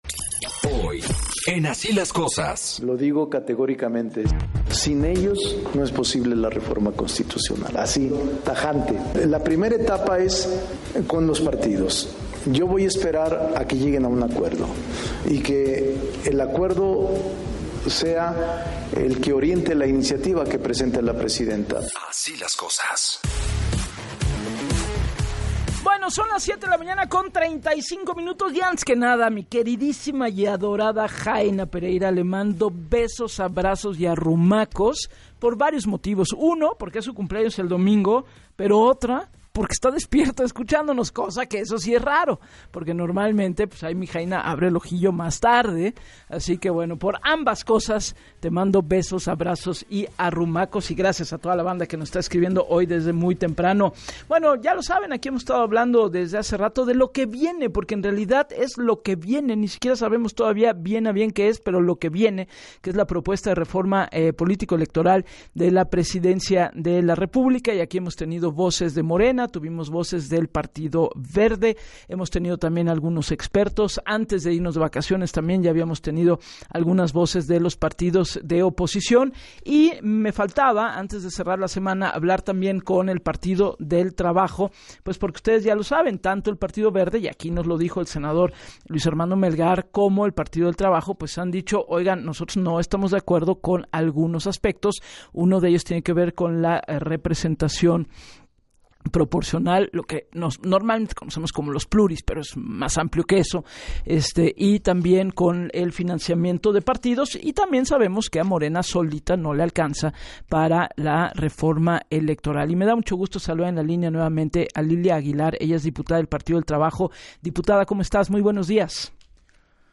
En entrevista para Así las Cosas con Gabriela Warkentin, Aguilar Gil sostuvo que “no se puede opinar sobre lo que no existe”, en referencia a una eventual reforma electoral.